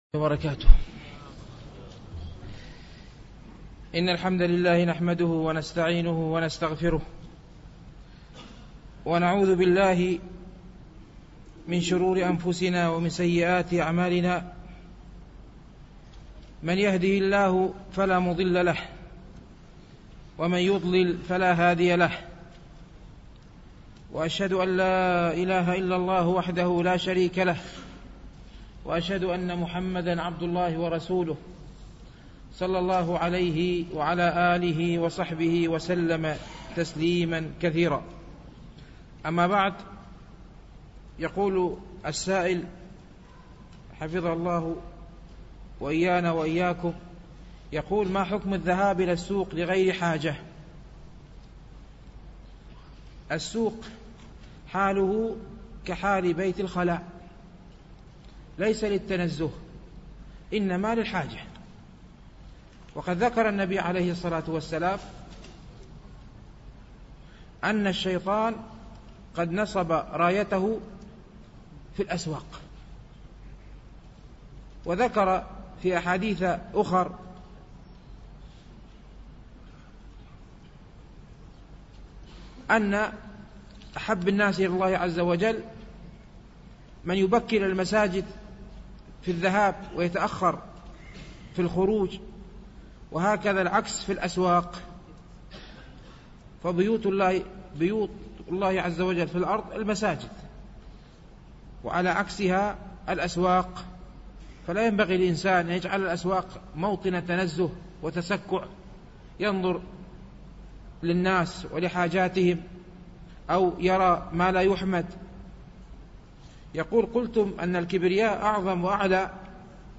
التنسيق: MP3 Mono 22kHz 32Kbps (CBR)